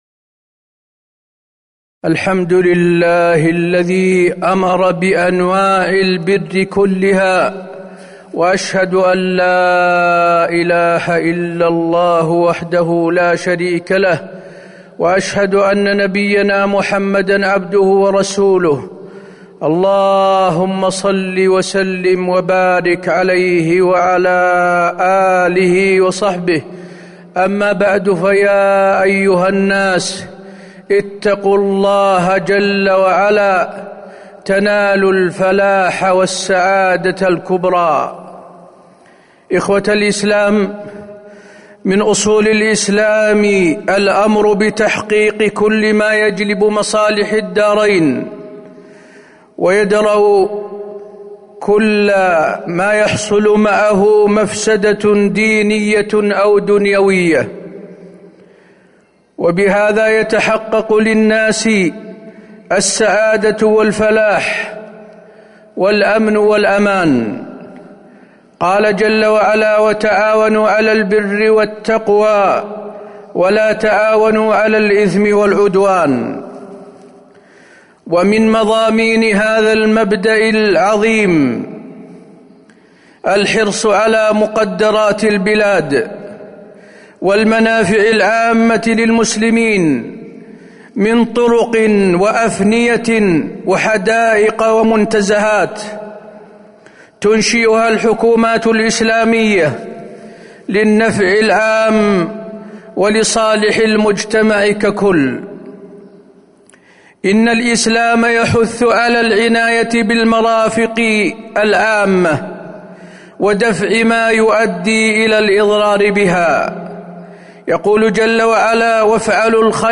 تاريخ النشر ٢٧ جمادى الأولى ١٤٤٣ هـ المكان: المسجد النبوي الشيخ: فضيلة الشيخ د. حسين بن عبدالعزيز آل الشيخ فضيلة الشيخ د. حسين بن عبدالعزيز آل الشيخ كف الأذى عن المسلمين